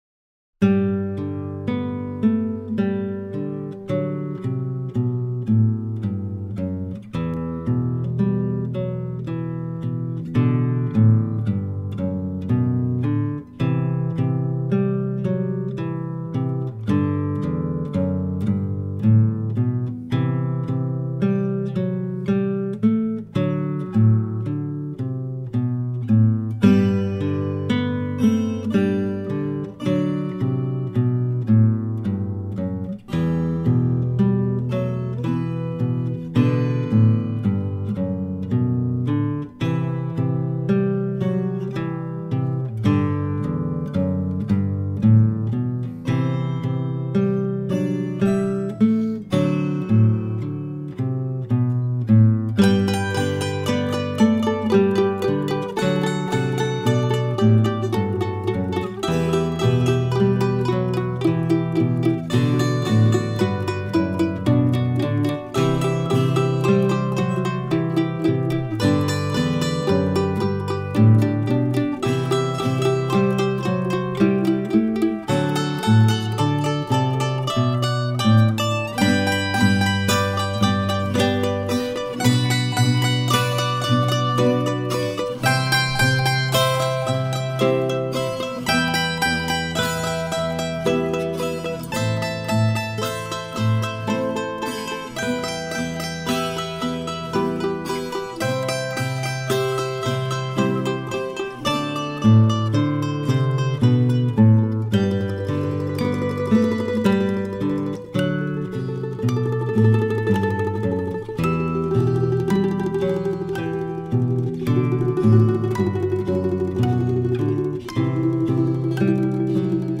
Colombiana